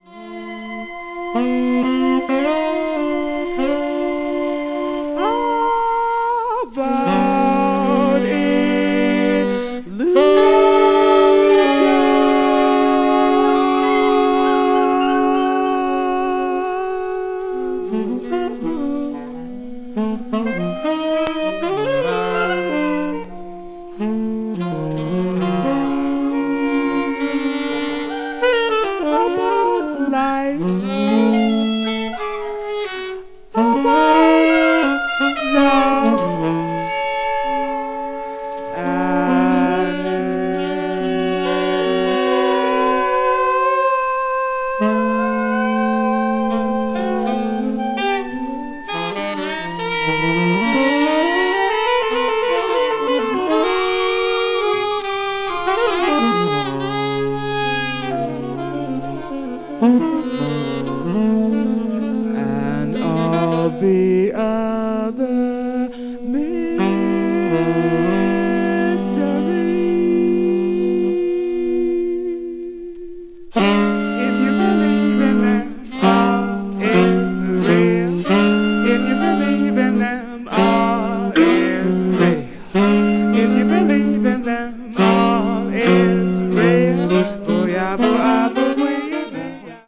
avant-jazz   free improvisation   free jazz